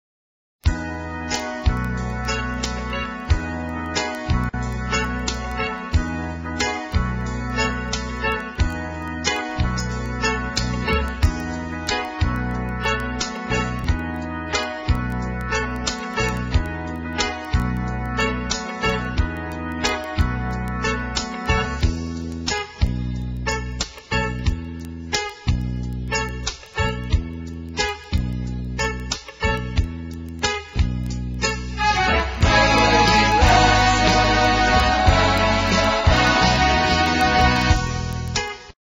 NOTE: Background Tracks 7 Thru 12